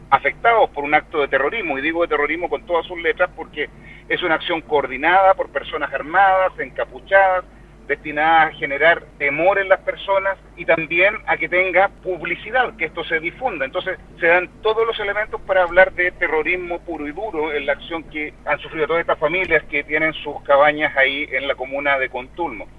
El Gobernador Regional del Bío Bío, Rodrigo Díaz criticó la tardanza en reaccionar del Gobierno, exigió seguridad a quienes, dijo, están encargados de brindarla y calificó este ataque como un acto terrorista.